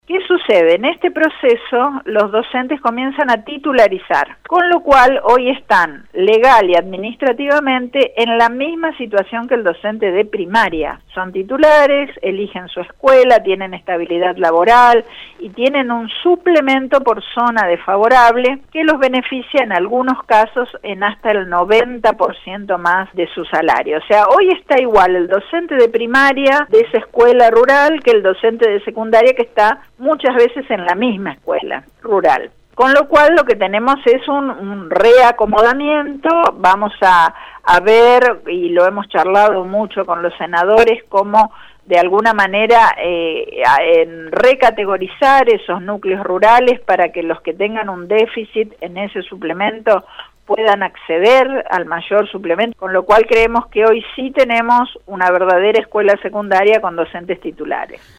Así lo indicó en Radio EME la ministra de Educación, Claudia Balagué.